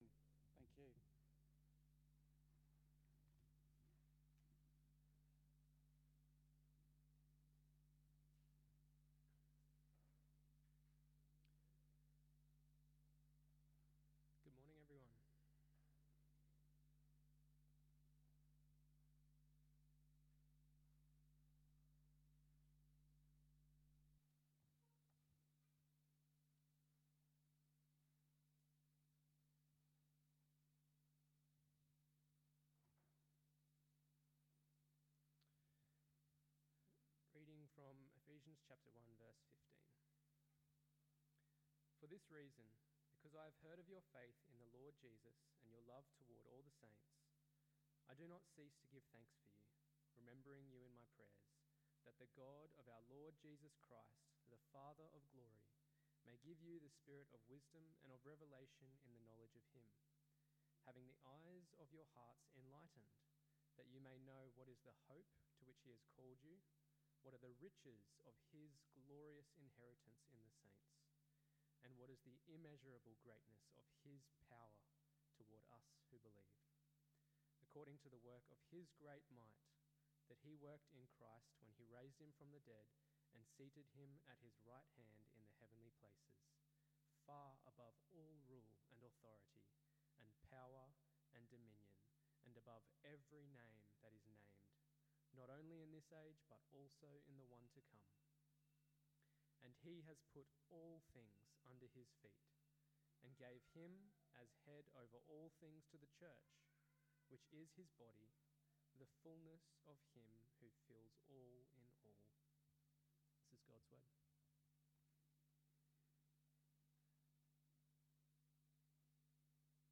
A Prayer For Understanding AM Service
sermon podcasts